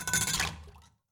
glas-water-ringing-5
bath bubble burp click drain dribble drop effect sound effect free sound royalty free Sound Effects